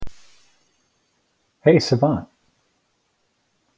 wake-word